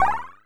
PowerUp1.wav